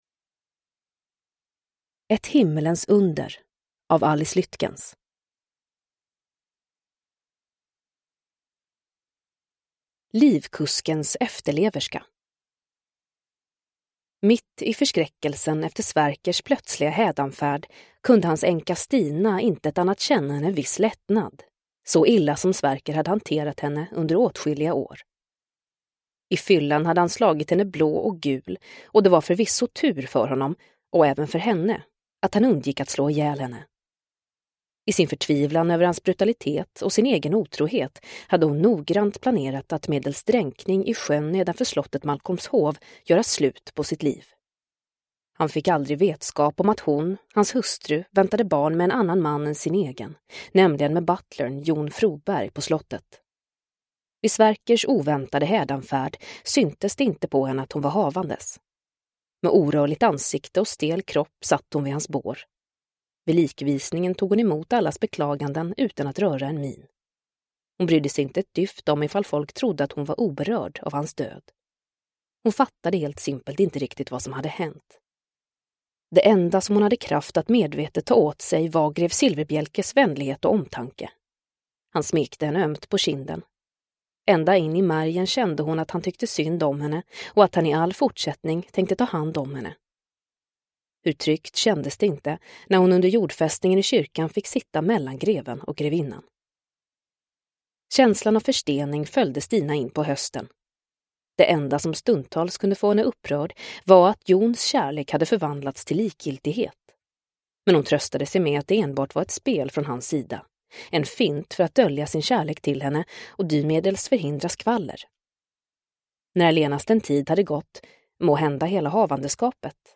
Ett himmelens under – Ljudbok – Laddas ner